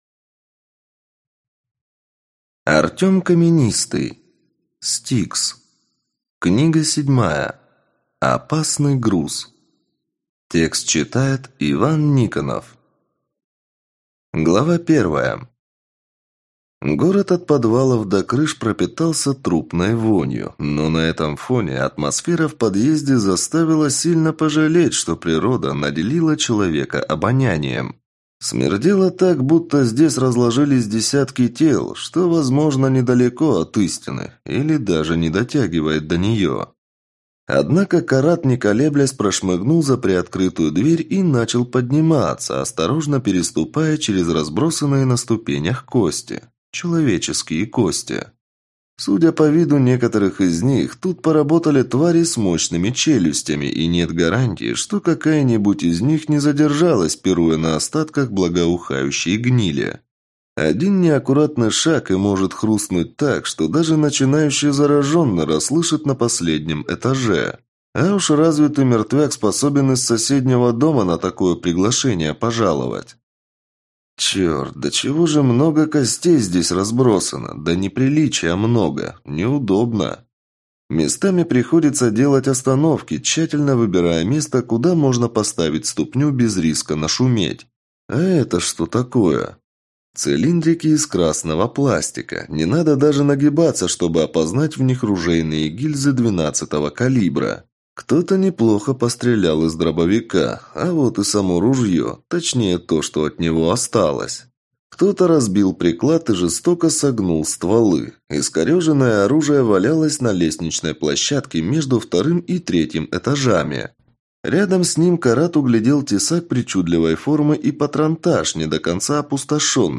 Аудиокнига S-T-I-K-S. Опасный груз - купить, скачать и слушать онлайн | КнигоПоиск